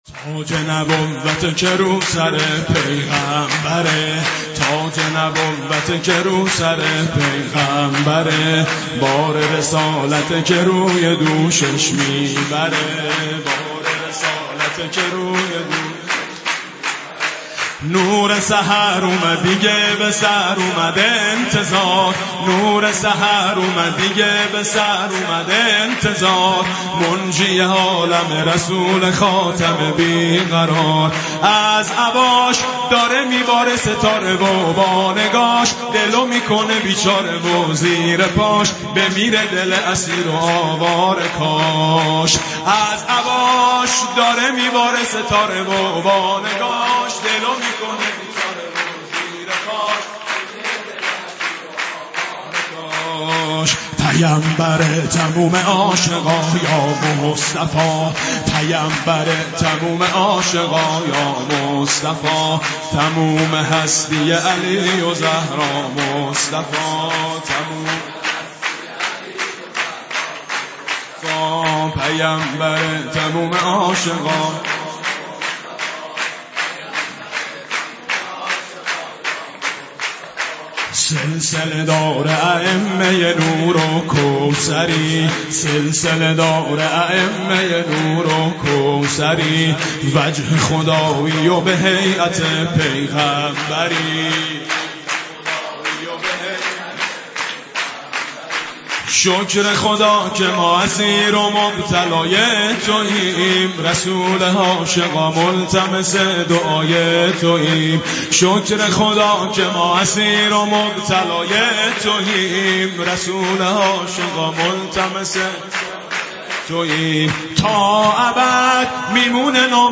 برچسب ها: مبعث ، پیامبر ، رسالت ، مولودی خوانی